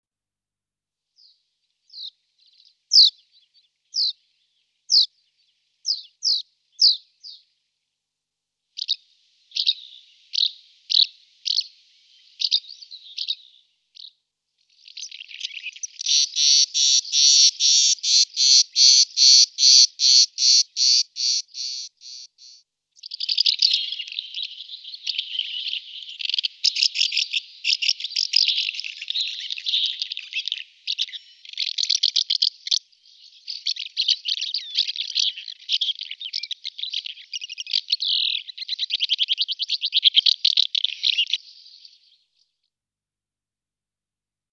Вы услышите их щебет, трели и другие голосовые реакции, которые помогут вам ближе познакомиться с этими удивительными птицами.
Шум крыльев городской ласточки в полете